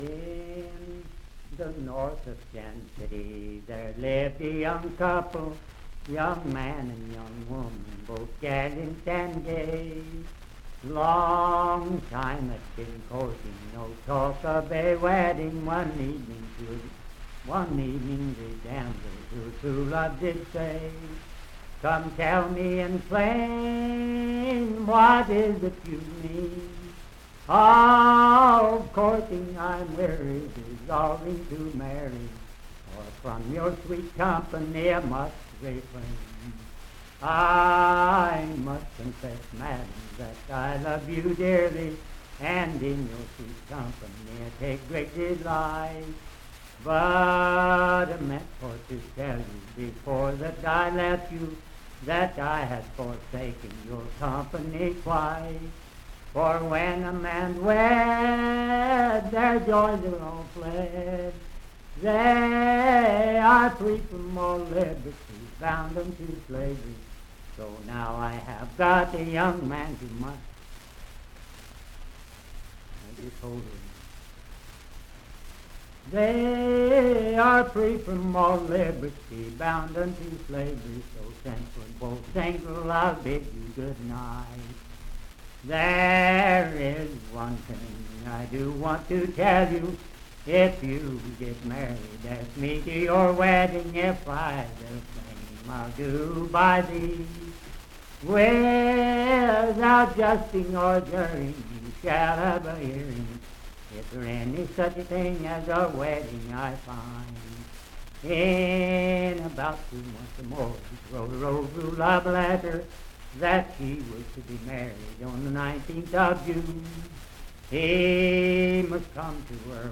Unaccompanied vocal music and folktales
Verse-refrain 11(4).
Voice (sung)
Wood County (W. Va.), Parkersburg (W. Va.)